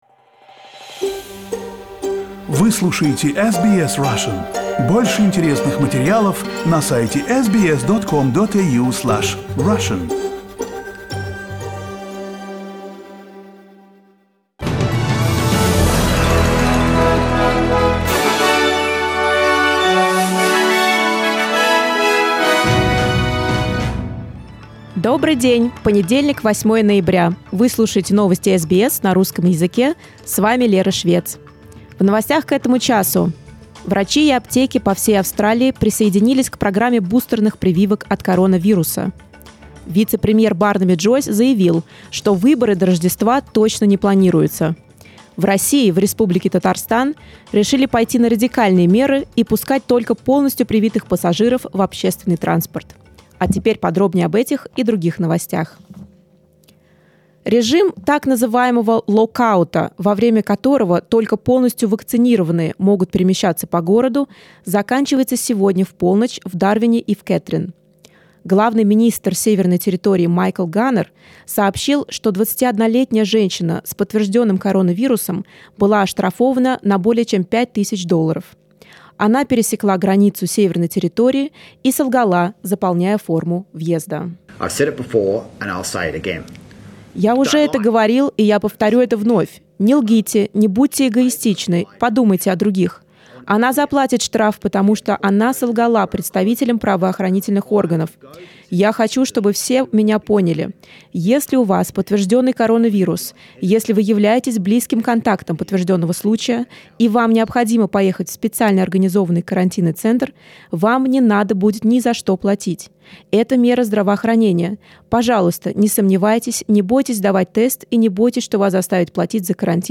SBS News in Russian — 08.11